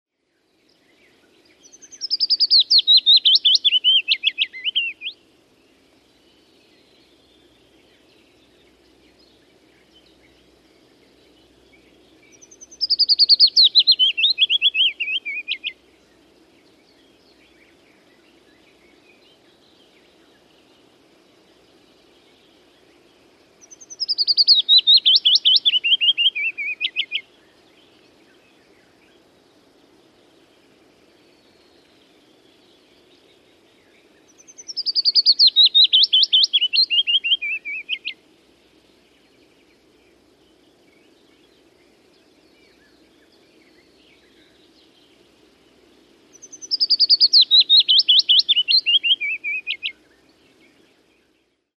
Kuuntele: Pajulintu